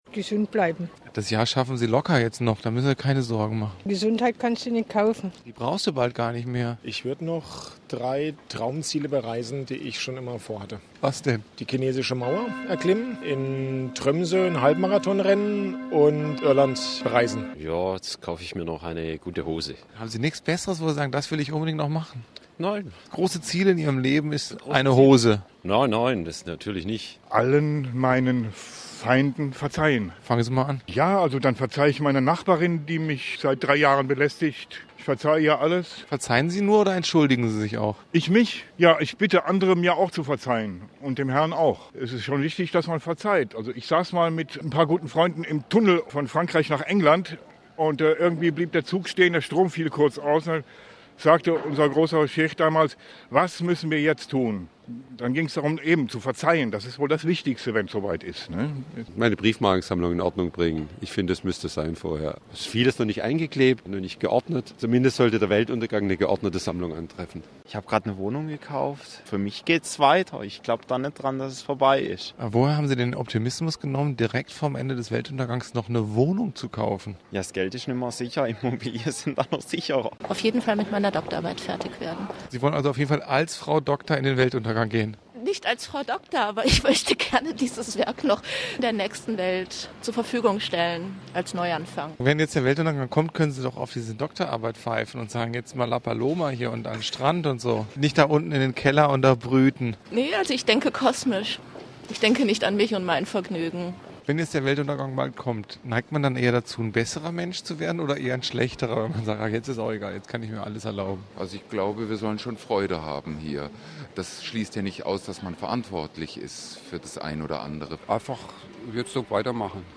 Im Fachsprech würde man vielleicht sagen: das sind „moderierte Umfragen“ oder „Gesprächscollagen“ oder „Talk-Beiträge“.
moderierte-umfrage-weltuntergang.mp3